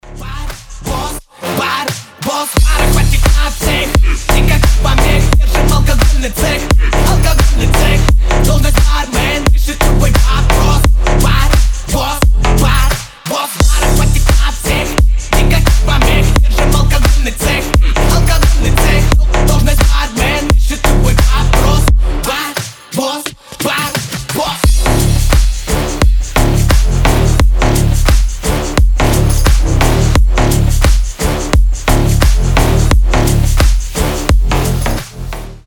• Качество: 320, Stereo
басы
G-House